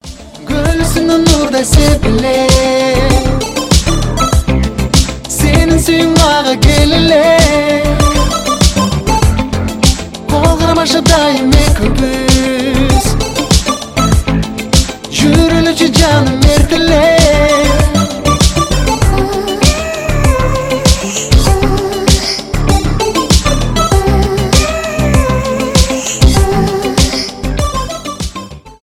красивый мужской голос , киргизские , поп